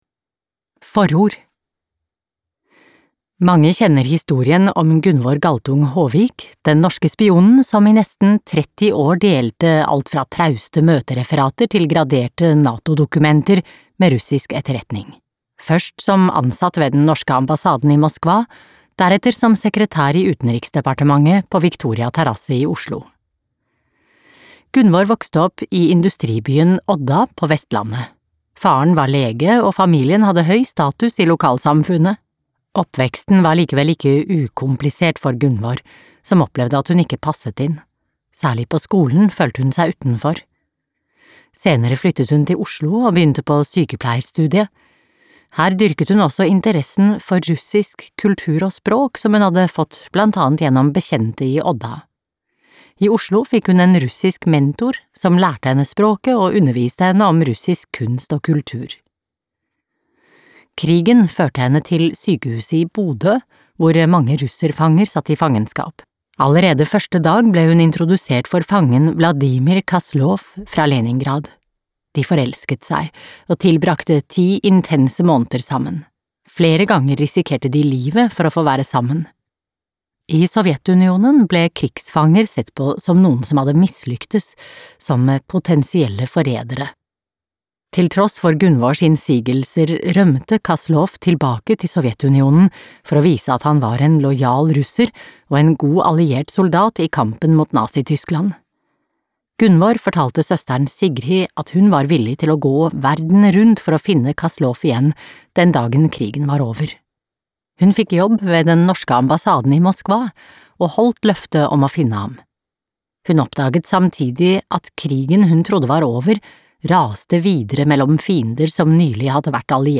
Kodenavn Greta - Gunvor Galtung Haavik – en norsk spionhistorie fra virkeligheten (lydbok)